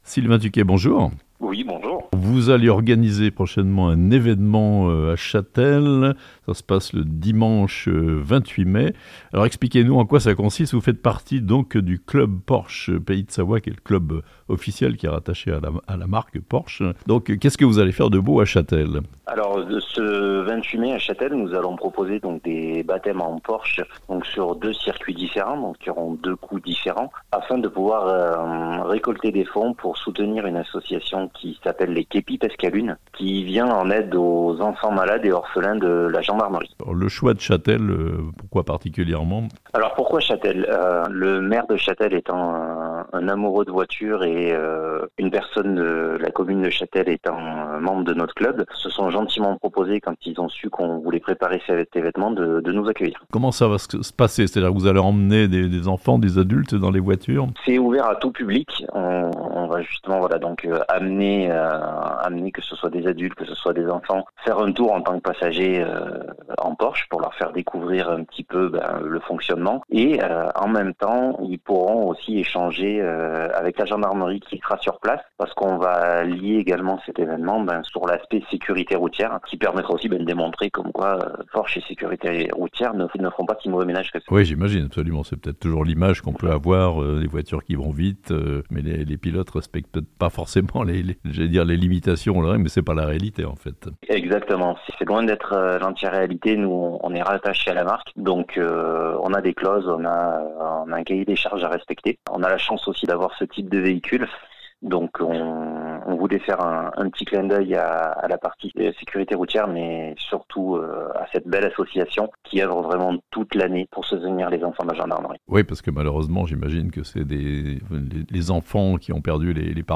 Châtel : "sur les képis de roues", une animation au profit des œuvres de la Gendarmerie nationale (interview)